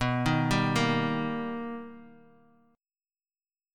Listen to B+M7 strummed